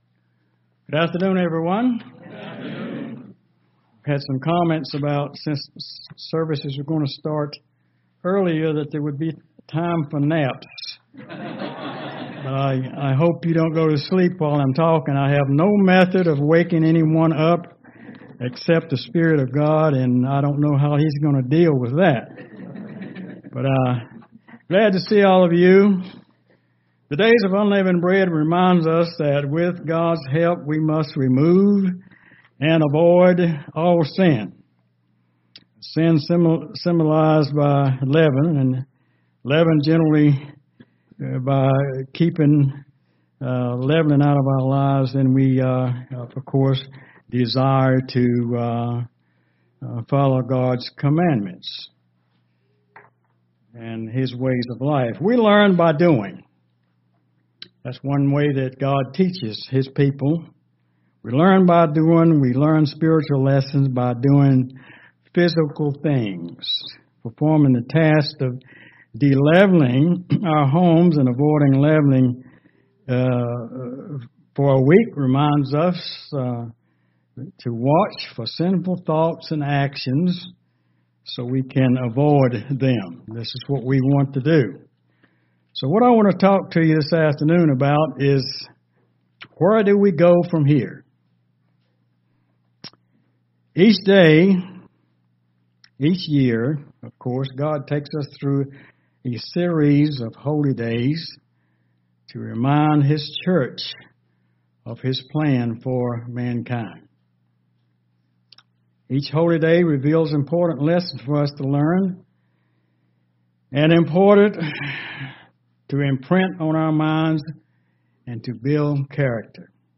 Sermons
Given in Charlotte, NC